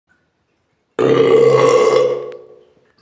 Loud Burp Funny Noise Sound Button: Unblocked Meme Soundboard
Play the iconic Loud Burp Funny Noise sound button for your meme soundboard!